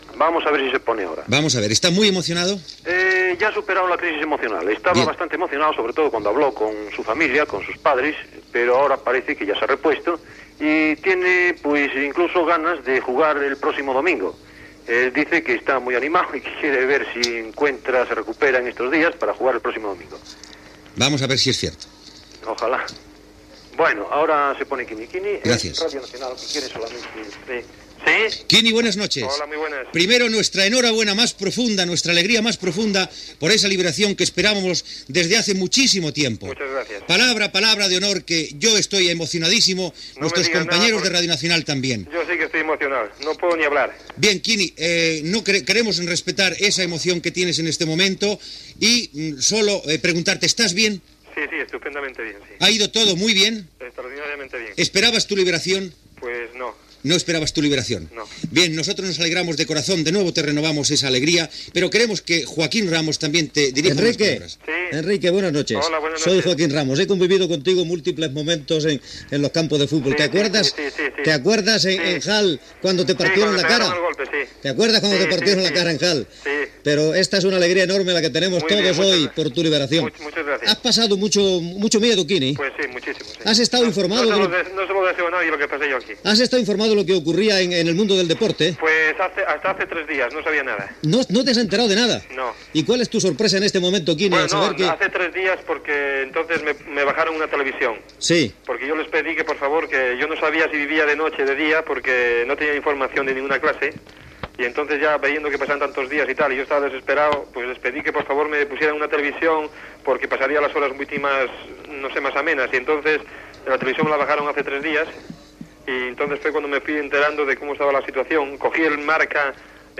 Entrevista al jugador de futbol Enrique Castro "Quini" recentment alliberat del segrest que va patir
Informatiu